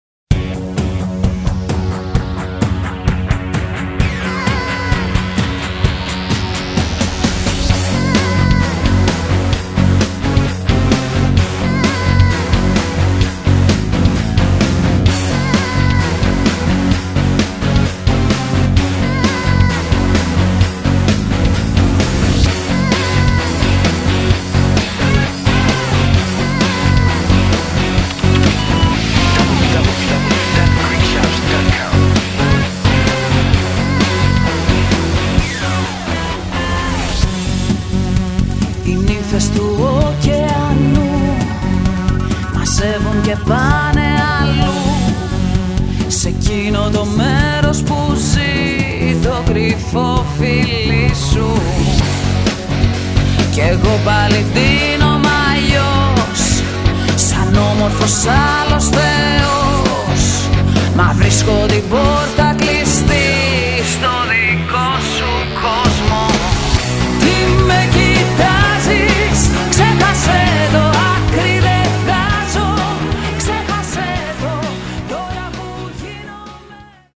Greek Rock/Pop band